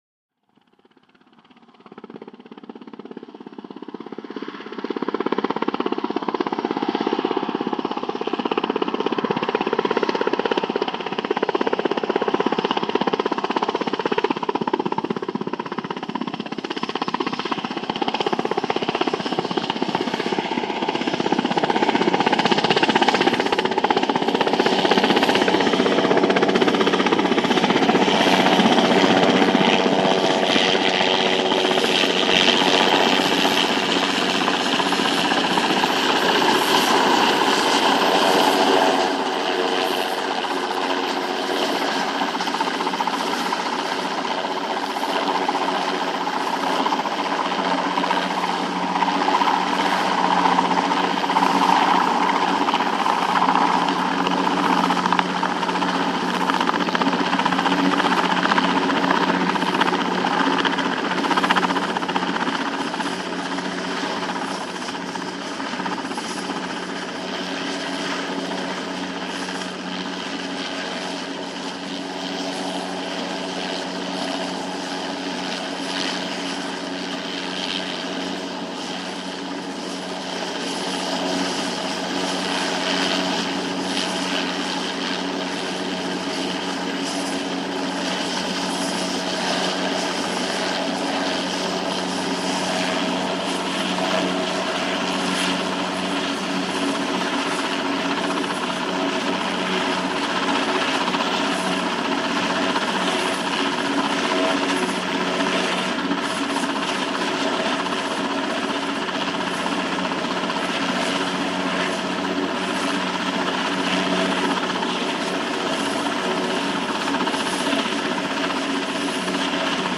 HELICOPTER AEROSPATIALE SA-341 GAZELLE: EXT: Approach, by, hover, by, away. Long hover with rotor thwops.